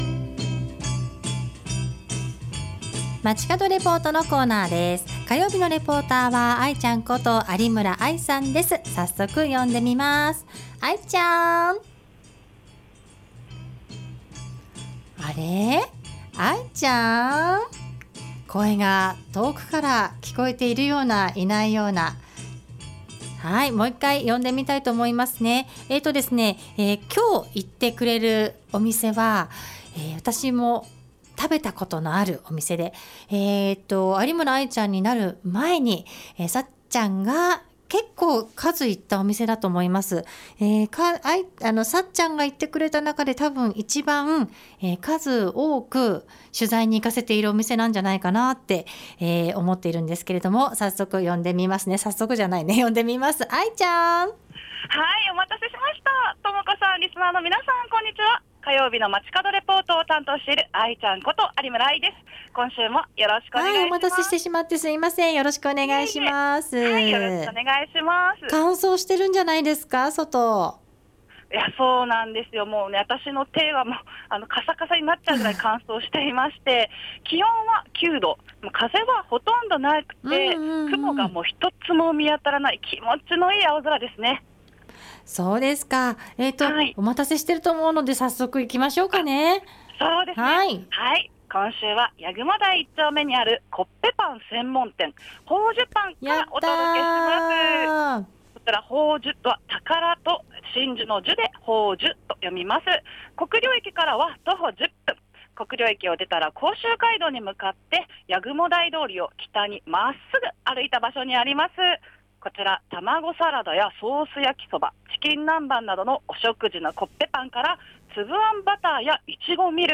今週は国領駅から歩いて10分、八雲台1丁目にあるコッペパン専門店「宝珠パン」からお届けしました！